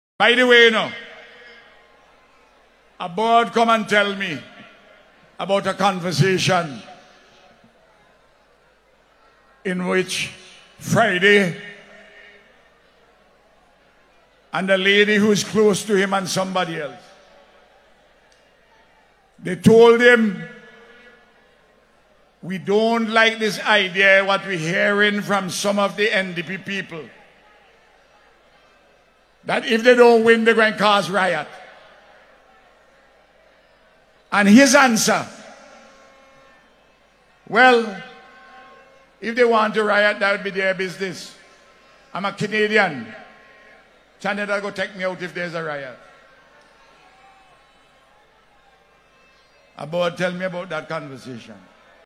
Speaking during a recent address, the Prime Minister said he had been warned that NDP supporters were allegedly prepared to create unrest in the event of a ULP victory.